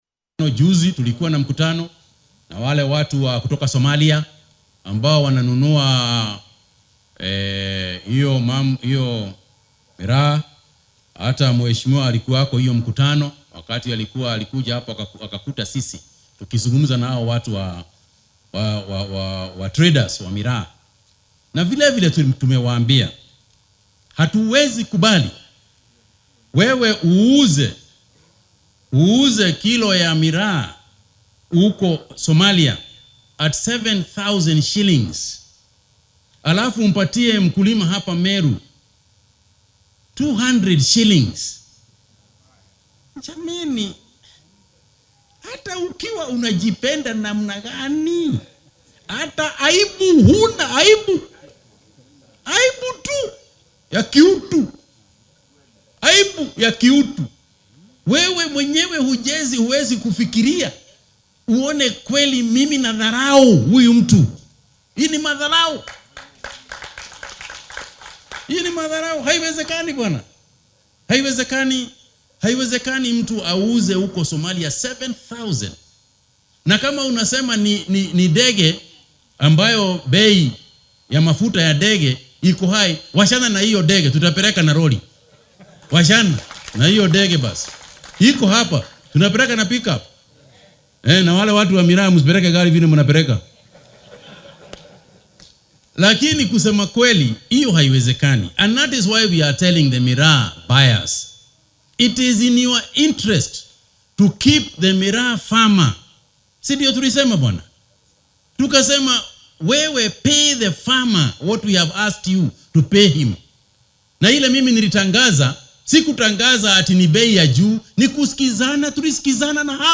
Wasiirka Wasaaradda Beeraha iyo Xoolaha dalka Mutahi Kagwe ayaa uga digay ganacsatada iyo kuwa wax dhoofiya ee ka ganacsada khaadka in ay u hoggaansamaan qiimaha ugu yar ee ay Wasaaraddu dejisay ama lagala laabto shatiyada ganacsiga. Isagoo ka hadlayay maalinta beeralayda ee ismaamulka Meru ayuu wasiirka sharaxay in ganacsatada u hoggaansami weyda qiimaha cusub laga doonayo inay dib u soo codsadaan oo ay muujiyaan qiimaha ay doonayaan inay u soo bandhigaan beeralayda.